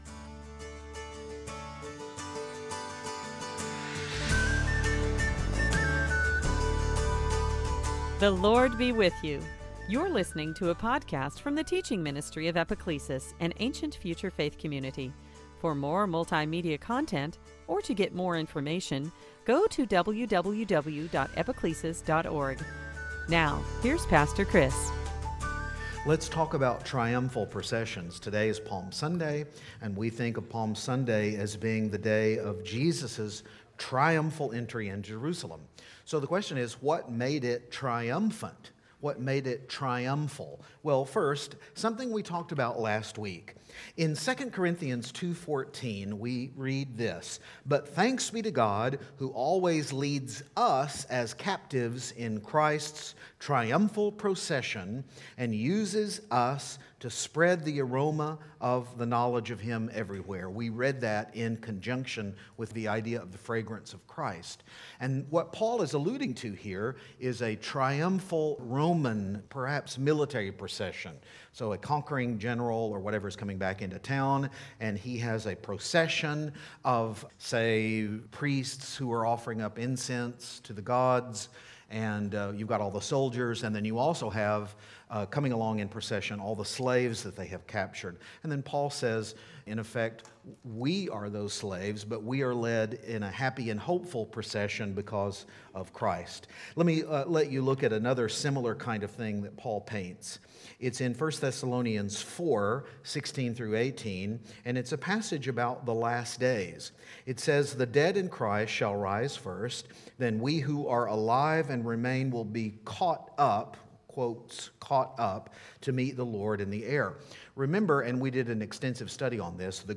Service Type: Palm Sunday